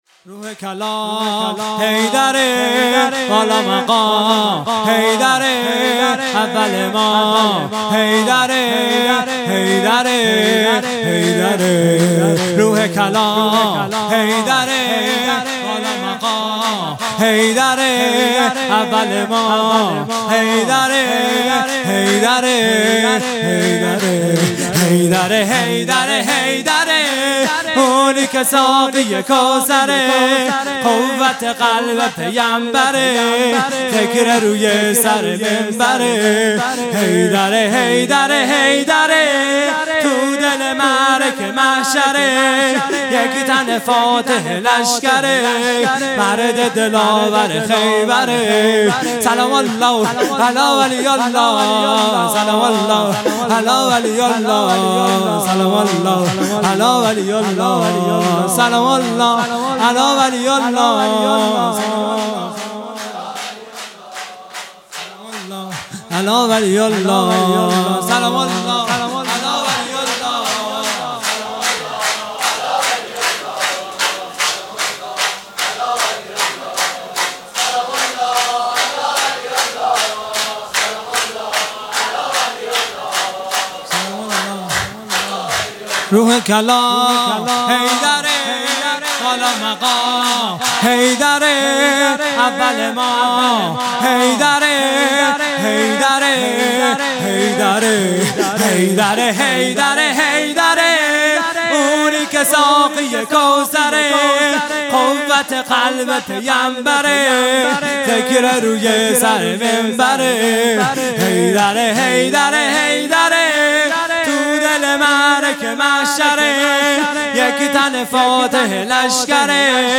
سرود | روح کلام حیدره | ۱۲ خرداد ۱۴۰۱
جلسۀ هفتگی | ولادت حضرت معصومه(سلام الله علیها) | پنجشنبه ۱۲ خرداد ۱۴۰۱